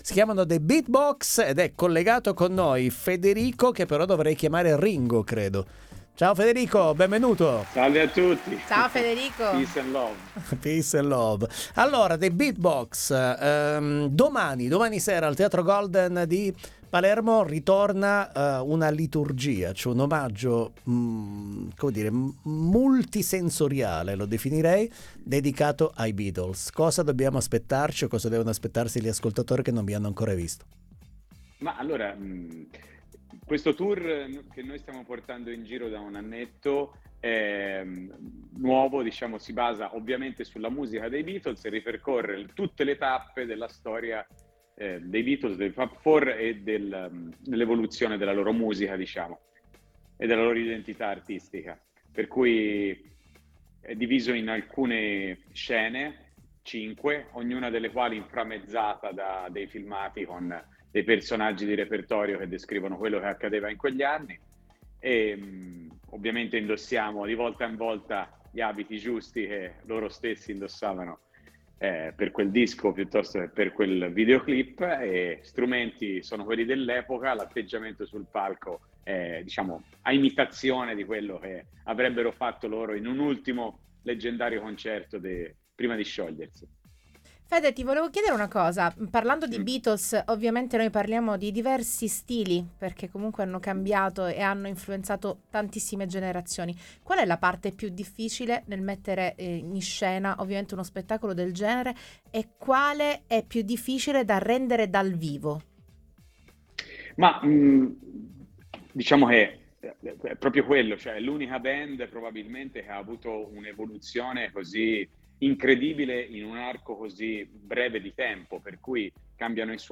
Interviste Tandem